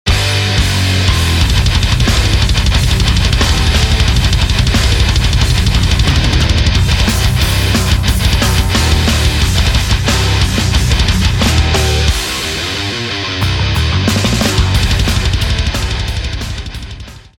Вложения partguitar.mp3 partguitar.mp3 407,1 KB · Просмотры: 207